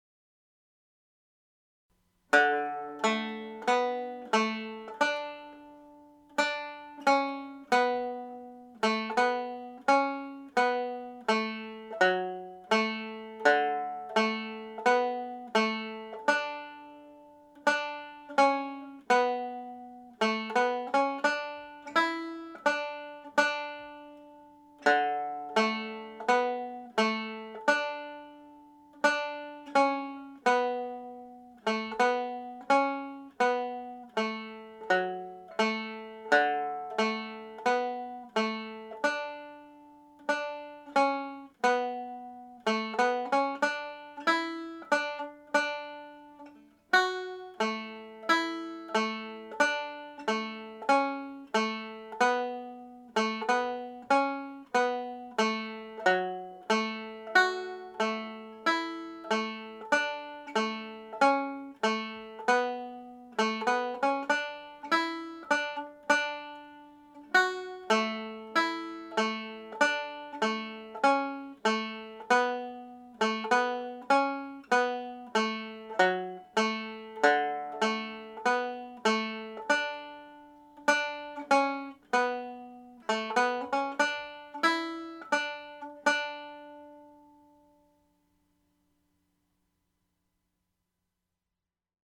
The polka is in the key of D major.
Denis Doody’s Polka played slowly